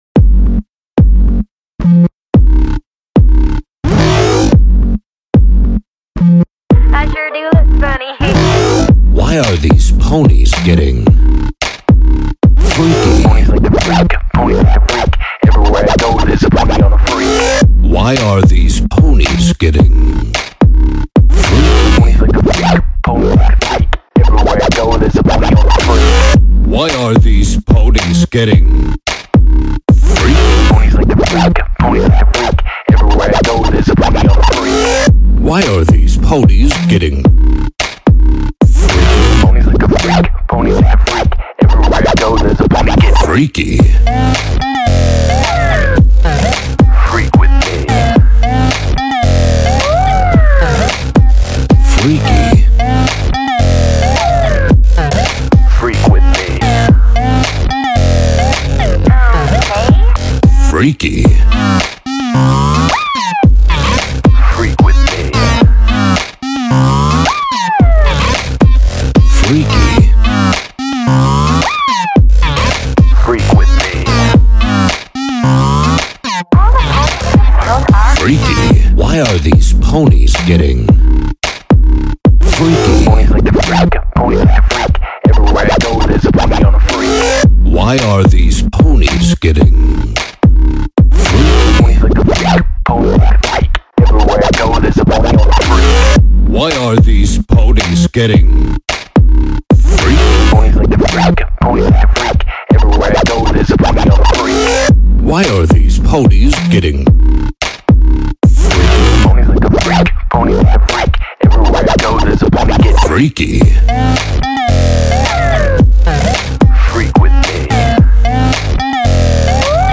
A funky collab with a friend of mine from Reddit.